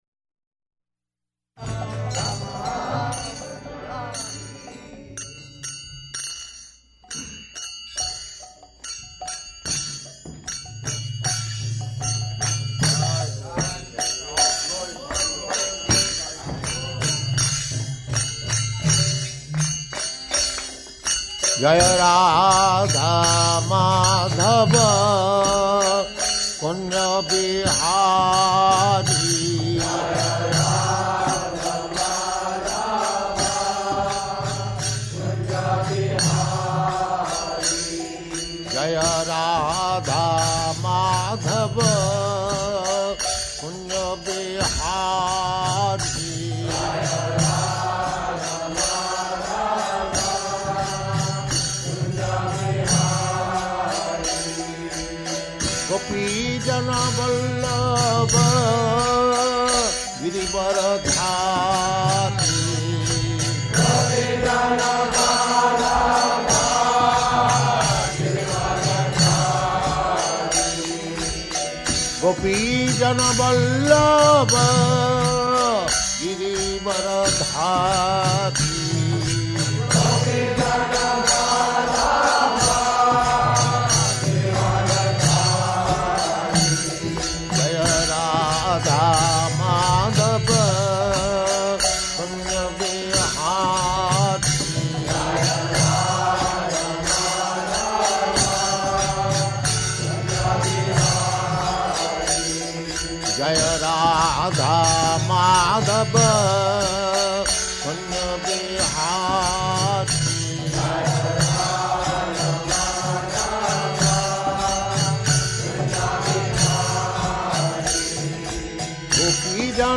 Location: Los Angeles
[leads chanting of verse] [Prabhupāda and devotees repeat] namo 'kiñcana vittāya nivṛtta-guṇa-vṛttaye ātmārāmāya śāntāya kaivalya-pataye namaḥ [ SB 1.8.27 ] Prabhupāda: Now ladies.
[ladies chant] That's all right.